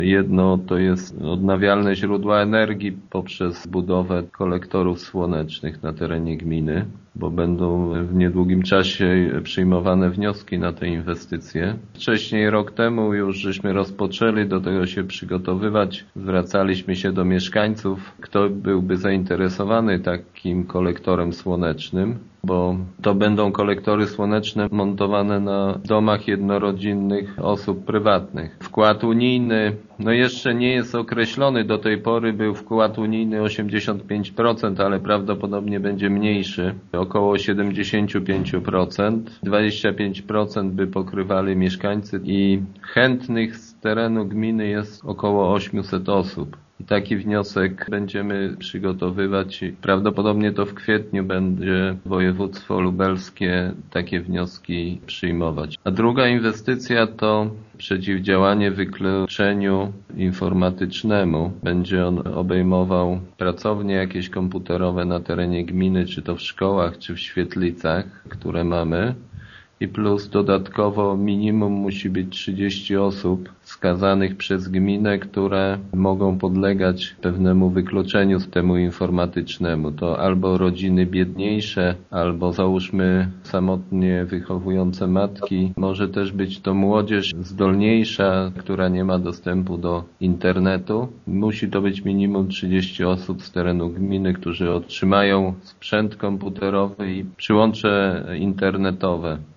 Wójt Jacek Anasiewicz wyjaśnia, że chodzi o dwa przedsięwzięcia: